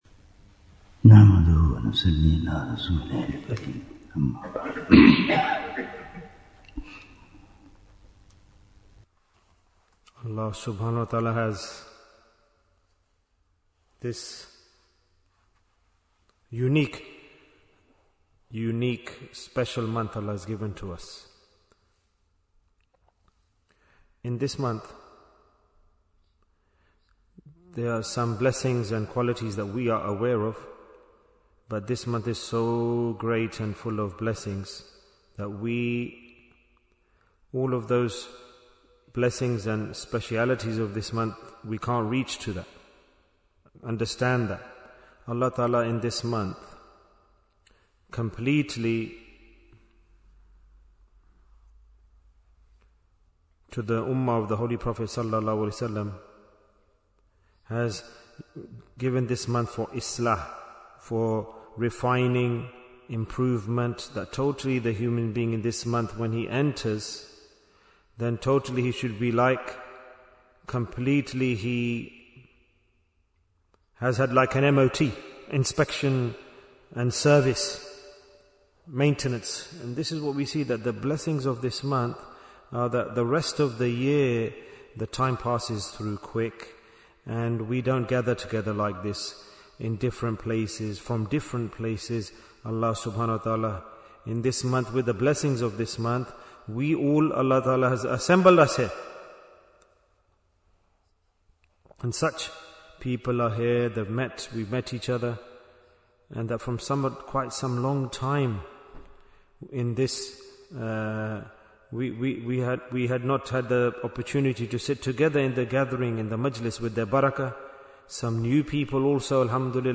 Jewels of Ramadhan 2025 - Episode 37 - The Last Asharah in Bradford Bayan, 32 minutes25th March, 2025